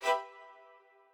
strings5_24.ogg